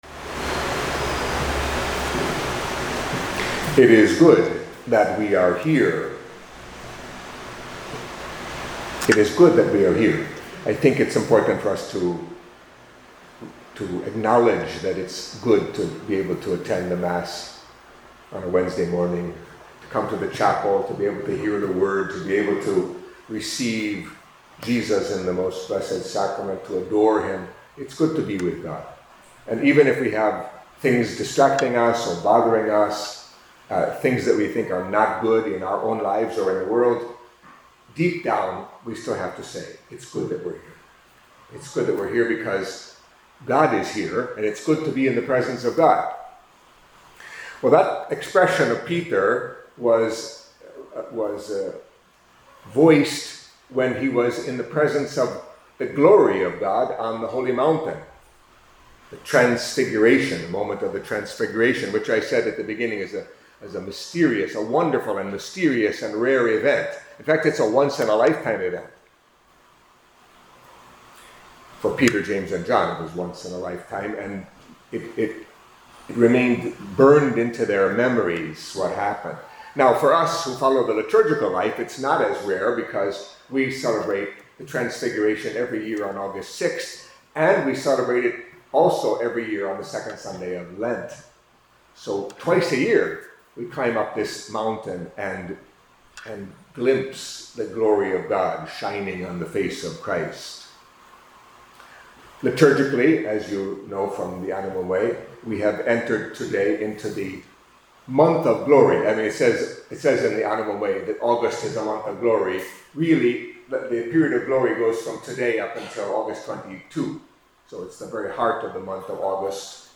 Catholic Mass homily for Feast of the Transfiguration of the Lord